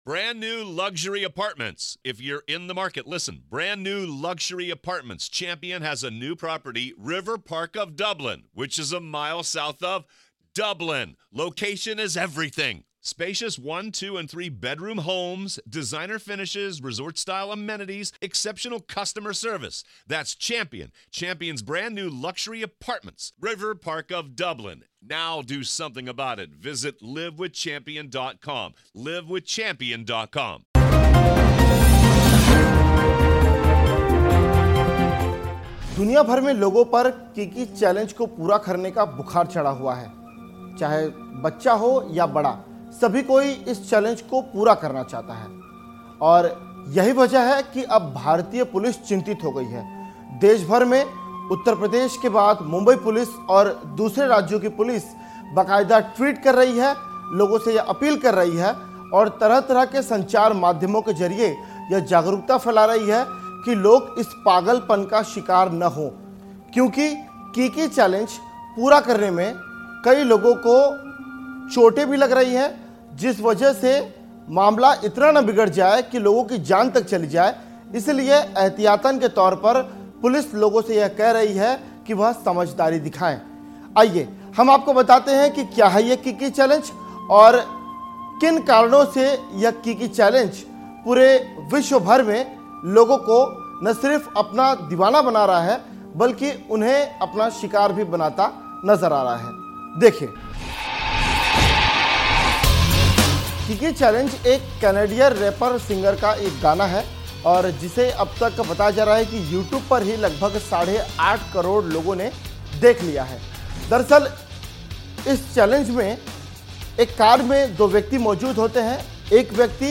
न्यूज़ रिपोर्ट - News Report Hindi / Kiki Challenge से है आपकी जान को खतरा, पुलिस ने जारी की चेतावनी